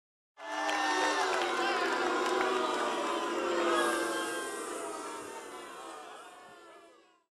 Crowd - Booing